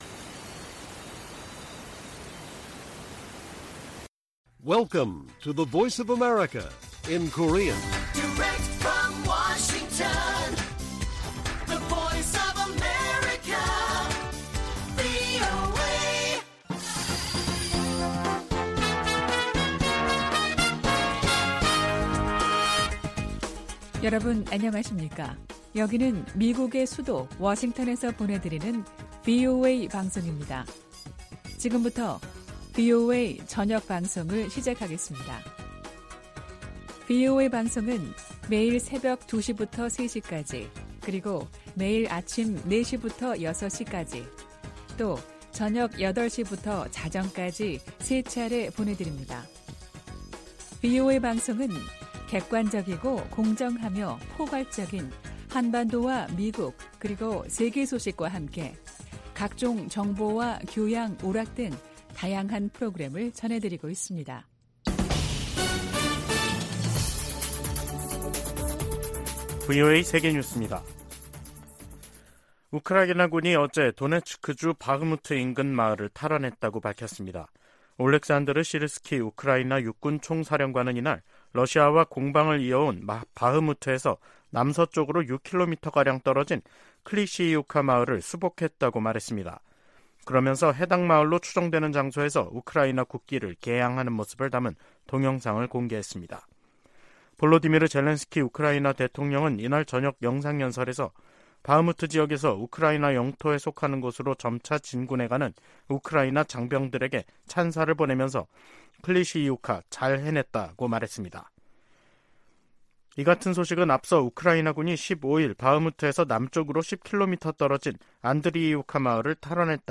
VOA 한국어 간판 뉴스 프로그램 '뉴스 투데이', 2023년 9월 18일 1부 방송입니다. 백악관은 북한과 러시아 사이에 무기 제공 논의가 계속 진전되고 있으며 예의 주시하고 있다고 밝혔습니다. 윤석열 한국 대통령은 북-러 군사협력 움직임에 대해 유엔 안보리 결의에 반한다며 한반도 문제 해결을 위한 중국의 역할을 거듭 촉구했습니다. 우크라이나 주변국들은 북한이 러시아에 우크라이나 전쟁에 필요한 무기를 제공 중이라는 주장에 촉각을 곤두세우고 있습니다.